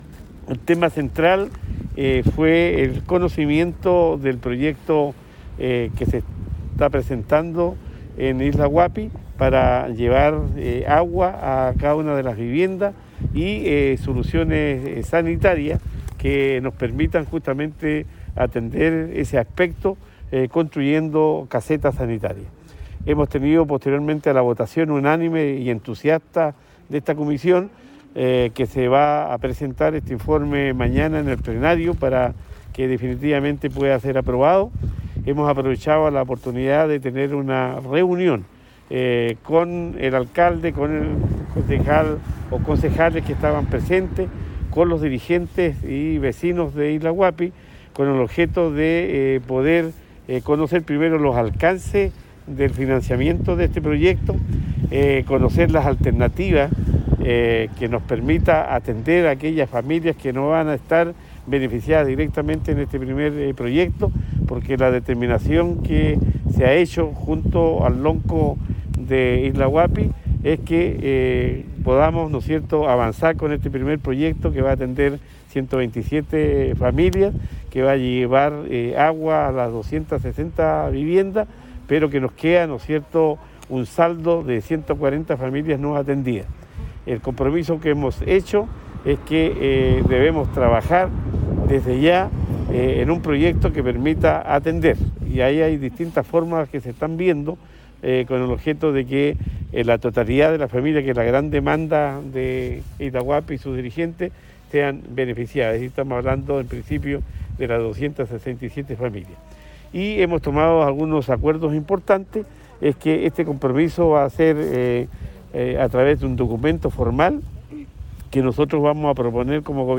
Gobernador-Luis-Cuvertino_Isla-Huapi.mp3